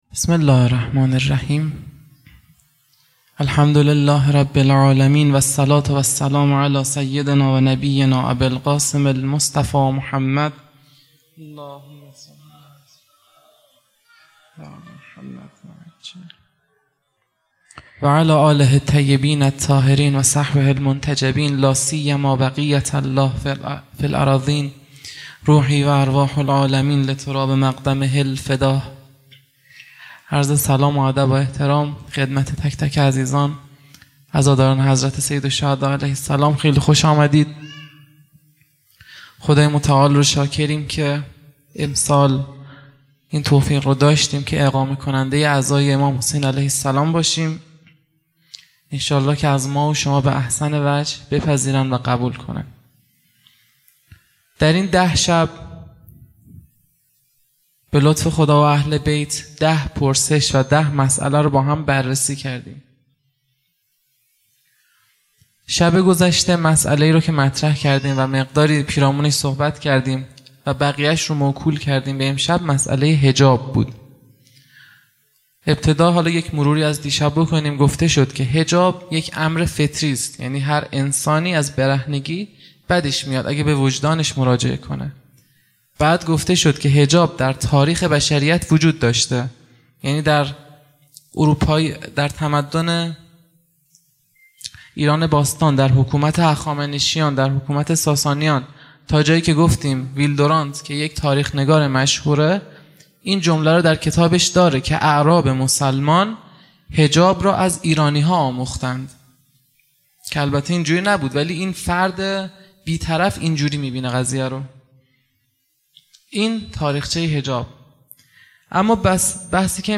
خیمه گاه - هیئت بچه های فاطمه (س) - پیش منبر | ۵ مرداد ماه ۱۴۰۲
شب عاشورا